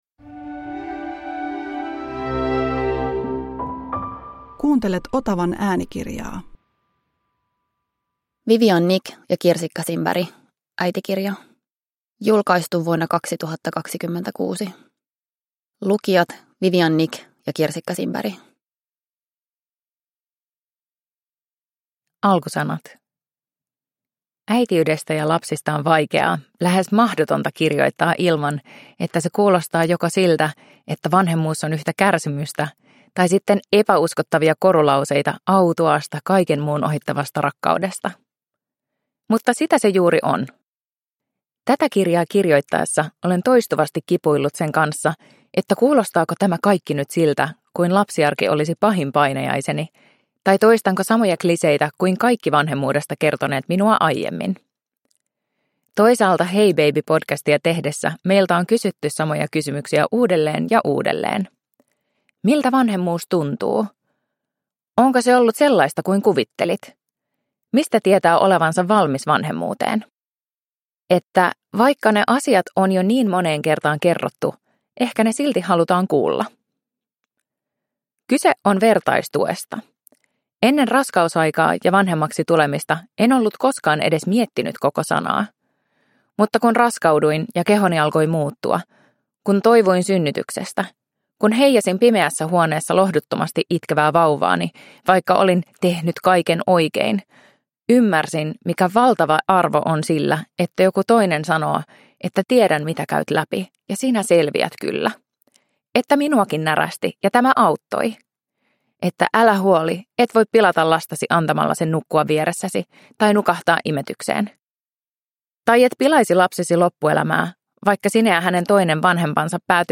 Äitikirja – Ljudbok